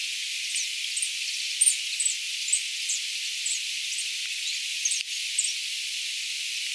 jotain tiltalttilintujen kommunikointia
Tämän jälkeen se toinen alkoi laulamaan.
tiltalttilintu_nakee_toisen_tiltalttilinnun_jotain_kommunikointia.mp3